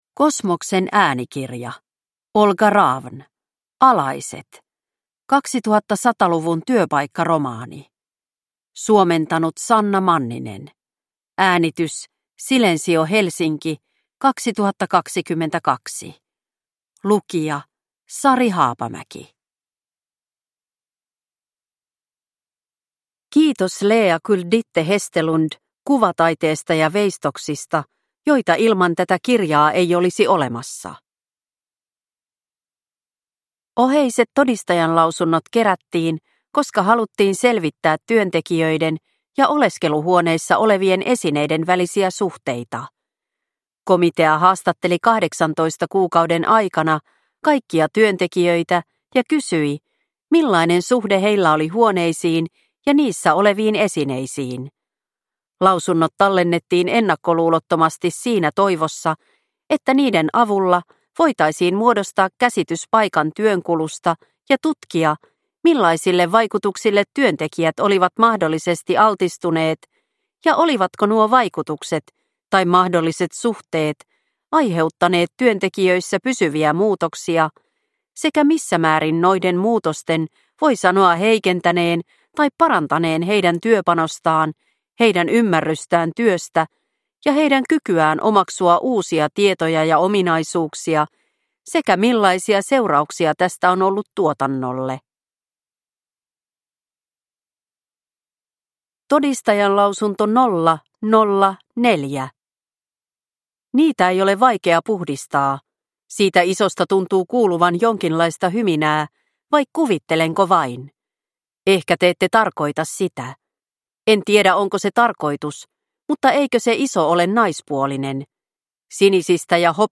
Alaiset – Ljudbok – Laddas ner